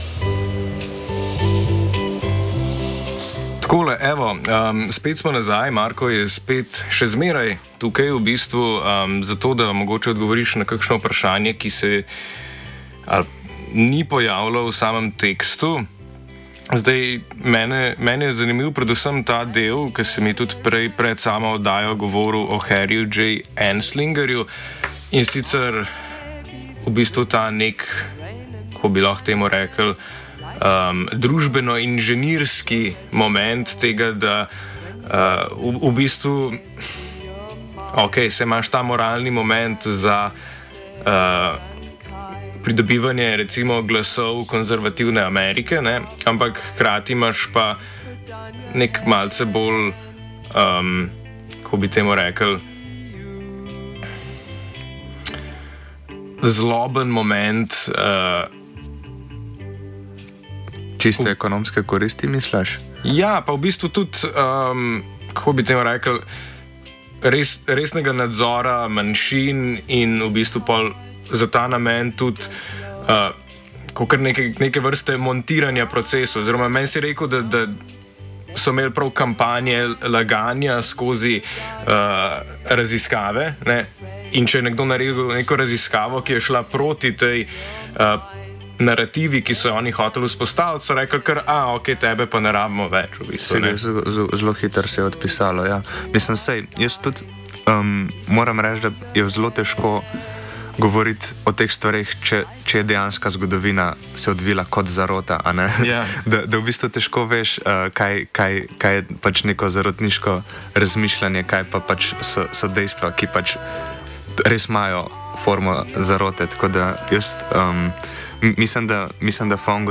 pogovor.mp3